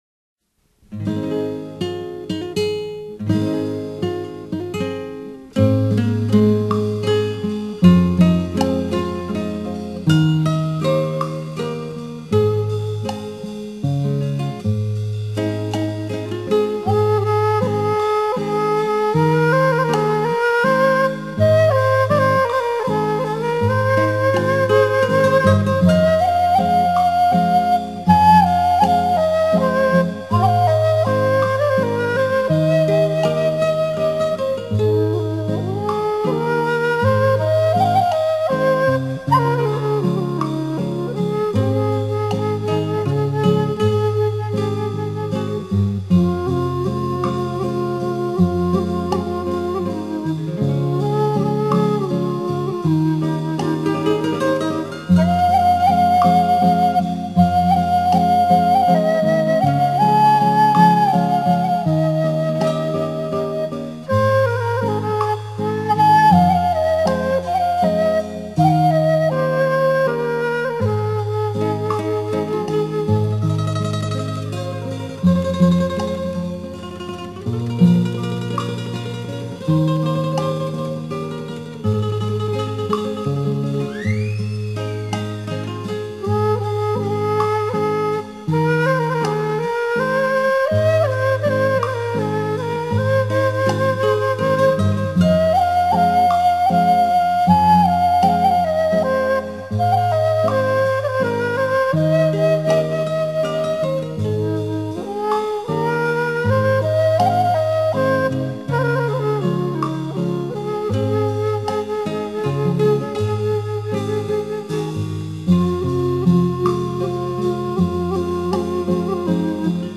缥渺的山水画的意境